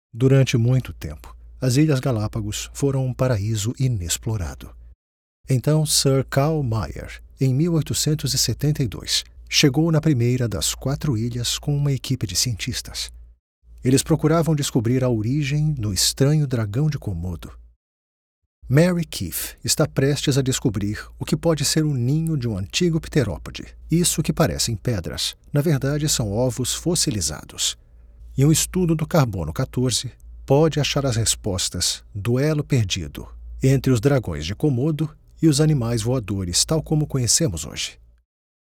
Naturelle, Amicale, Fiable, Corporative, Accessible
Vidéo explicative